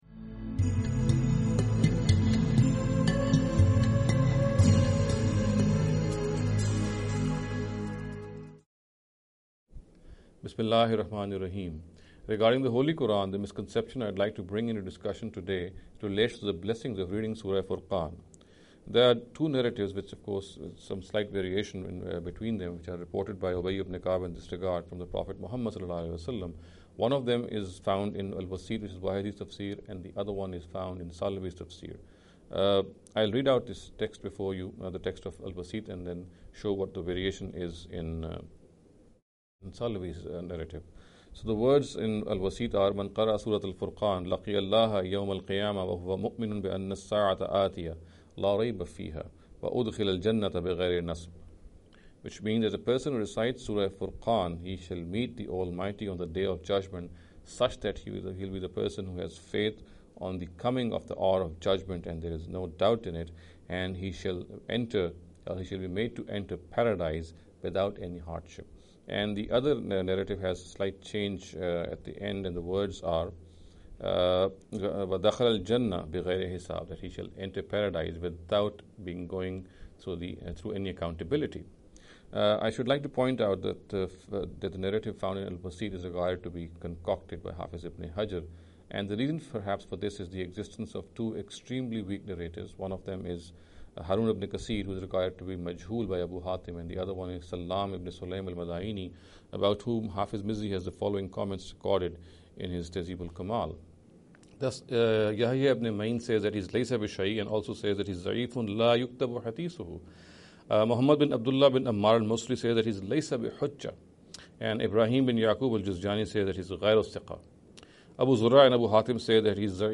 This lecture series will deal with some misconception regarding the Holy Quran. In every lecture he will be dealing with a question in a short and very concise manner.